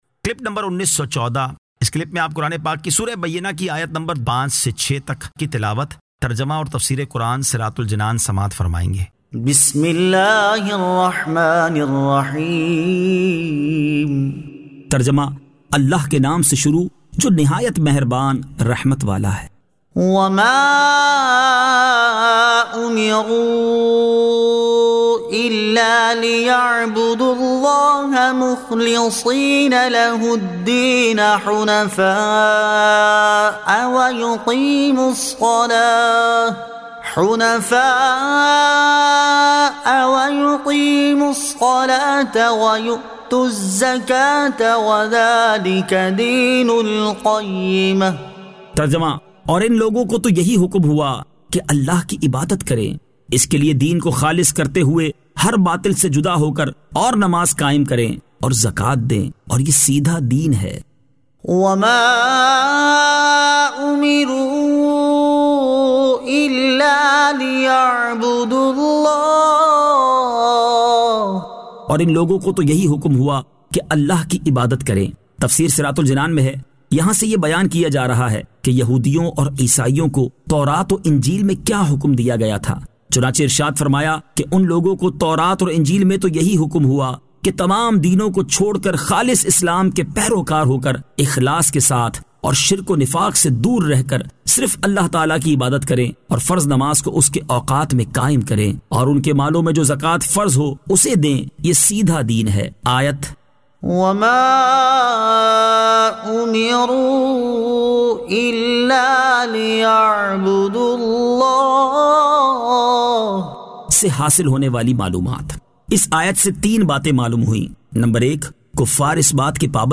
Surah Al-Bayyinah 05 To 06 Tilawat , Tarjama , Tafseer